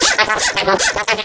tick_vo_05hurt.ogg